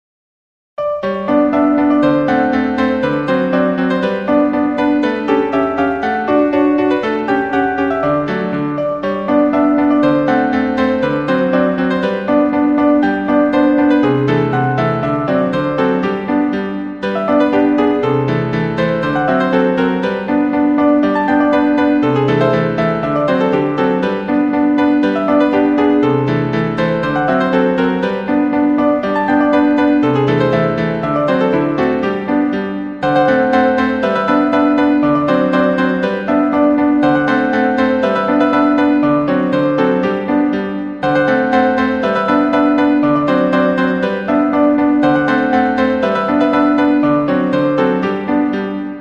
We selected instruments that sounded like upright Victorian pianos; in many cases, the lyrics line is played back as a honky-tonk piano, while the supporting bass and treble lines were kept to more sedate parlor pianos. The reverb is what we imagine these tunes would have sounded like had then been played in the Hotel Florence (with its Minton tile lobby) in 1885.